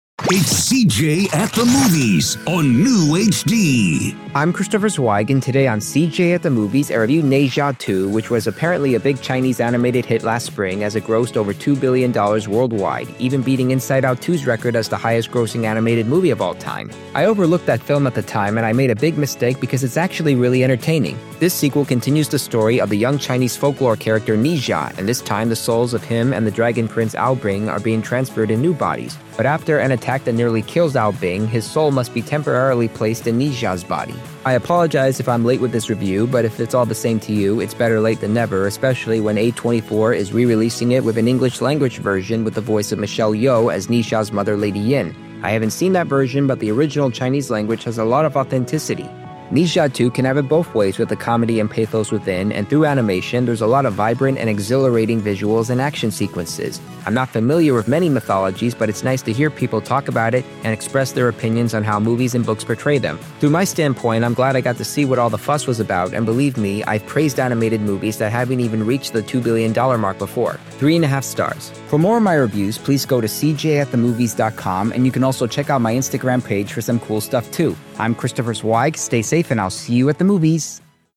reviews five dangerous movies on the air.